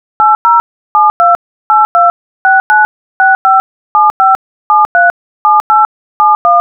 dtmf.wav